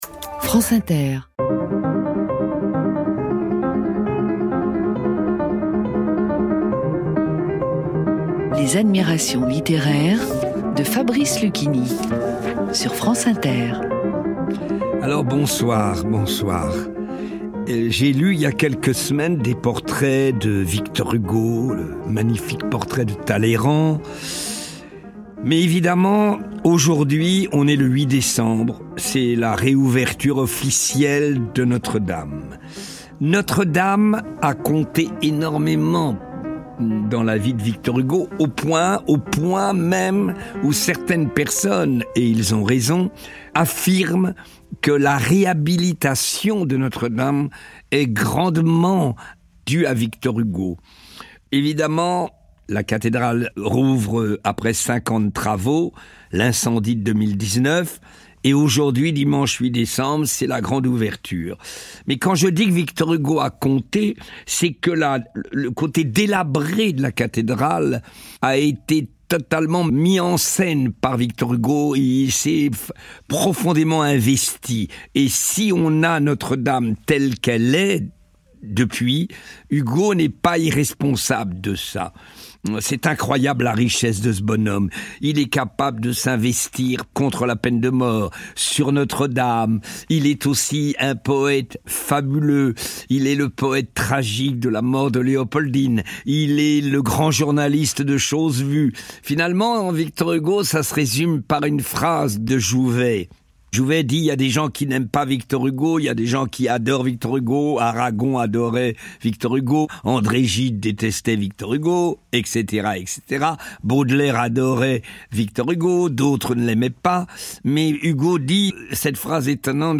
Fabrice Luchini lit Victor Hugo : L'oeuvre de Victor Hugo "une montagne", avec un extrait de "Notre-Dame de Paris"